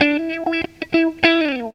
GTR 45 EM.wav